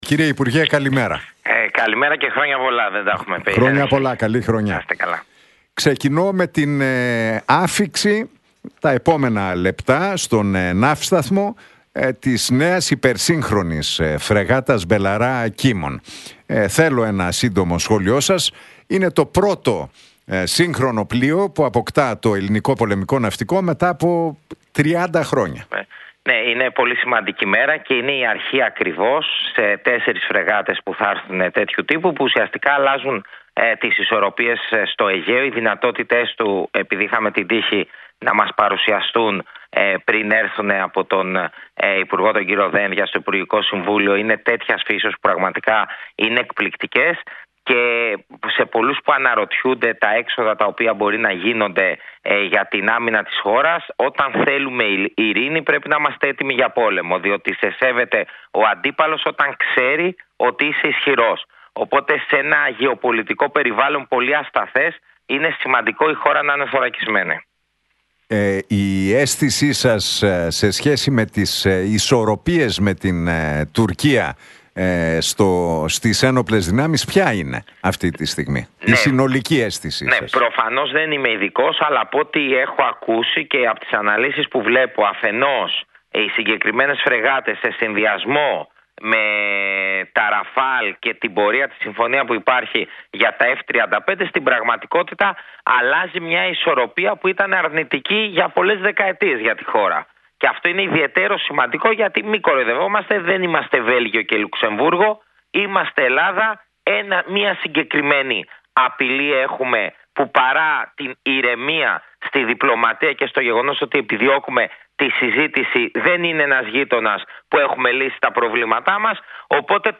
Για την υποδοχή της πρώτης ελληνικής Belharra και το μεταναστευτικό μίλησε ο υπουργός Μετανάστευσης και Ασύλου Θάνος Πλεύρης στην εκπομπή του Νίκου Χατζηνικολάου στον Realfm 97,8.